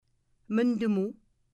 Mi’kmaw Pronunciations for Teaching About the Mi’kmaq